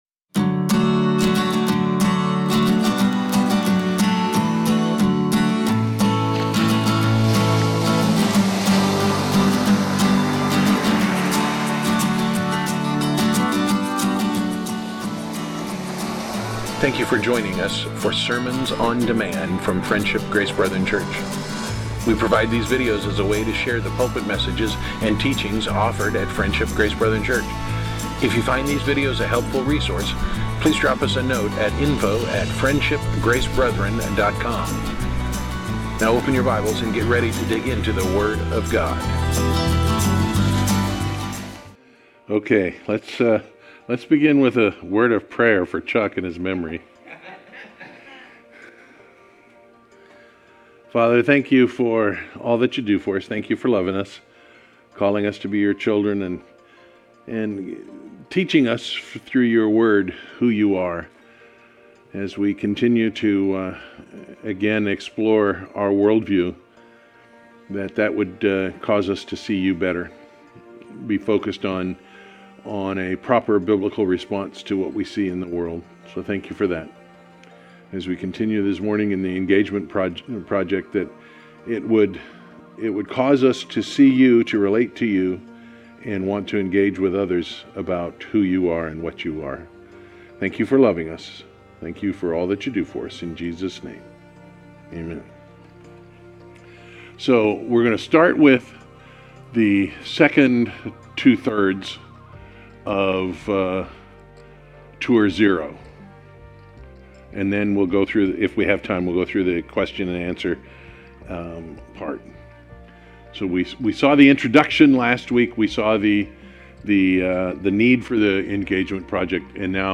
Series: Engagement Project, Sunday School